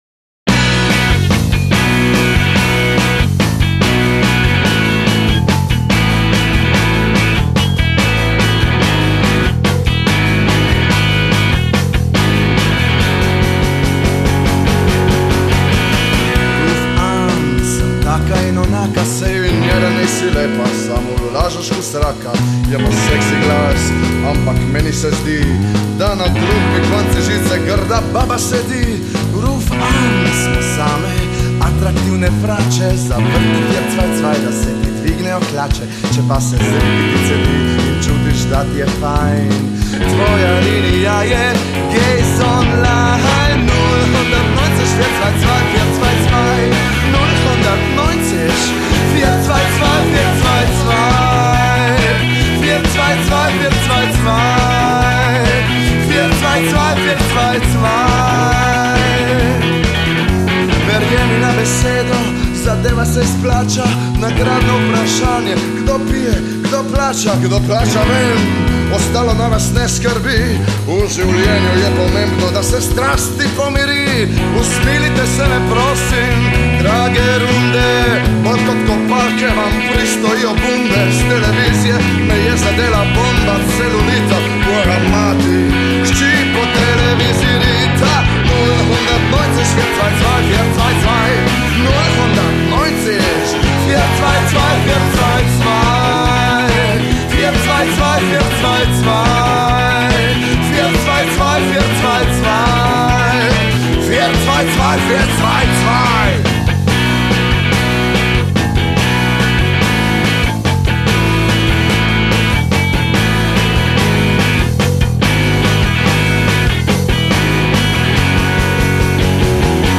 glas
kitara
bobni